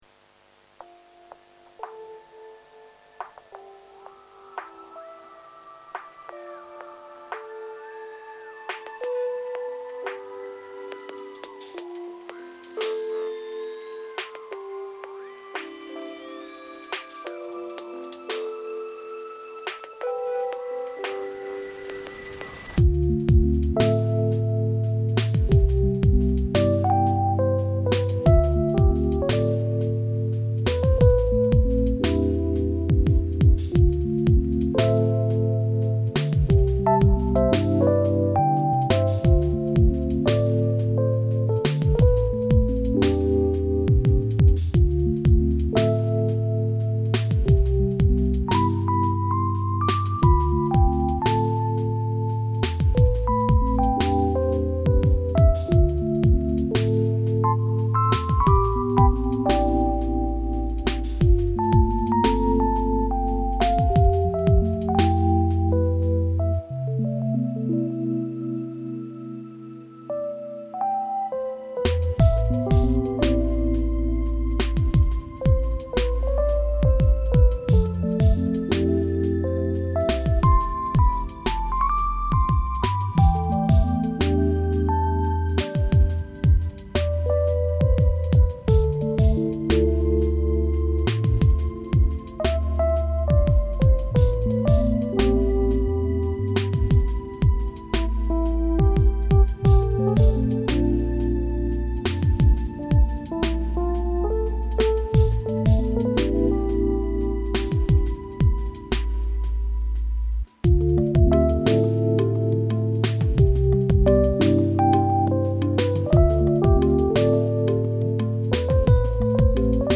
Download Song - Ambient / Chilly Jazz